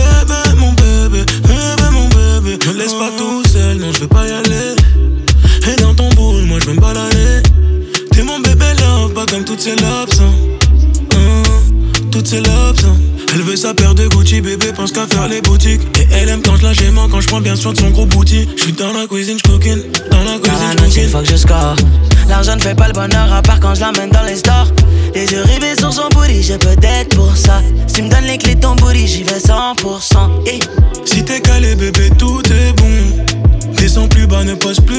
Жанр: Рэп и хип-хоп / Иностранный рэп и хип-хоп / Русские